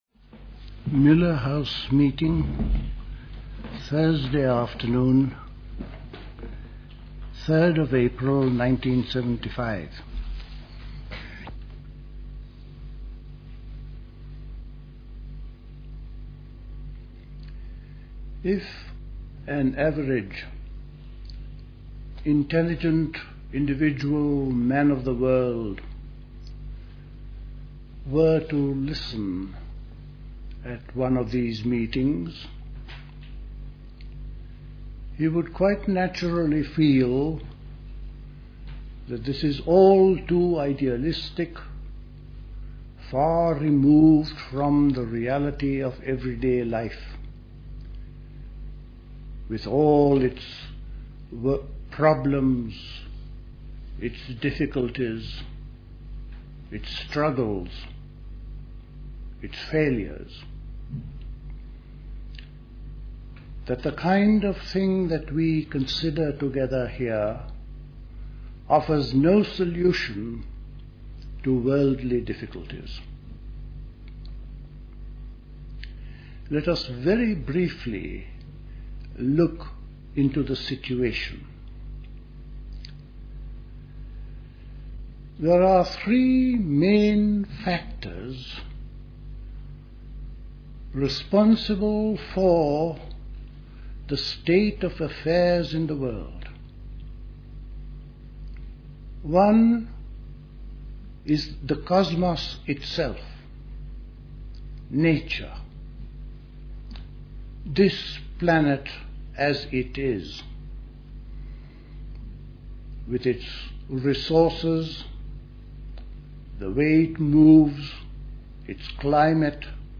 Recorded at the 1975 Elmau Spring School.